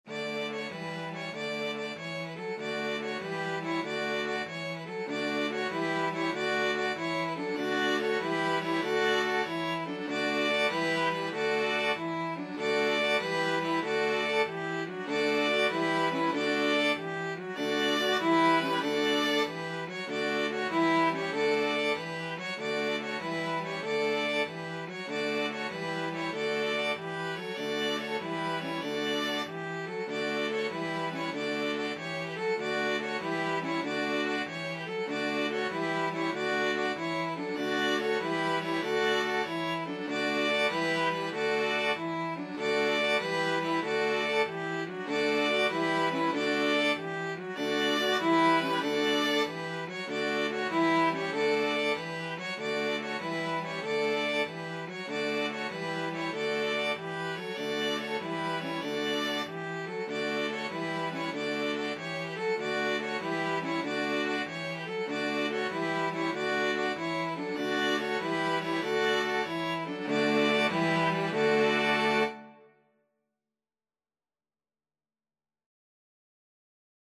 Free Sheet music for String Ensemble
Violin 1Violin 2Violin 3ViolaCello 1Cello 2
Traditional Music of unknown author.
D major (Sounding Pitch) (View more D major Music for String Ensemble )
Happily .=c.96
3/8 (View more 3/8 Music)
String Ensemble  (View more Easy String Ensemble Music)
Classical (View more Classical String Ensemble Music)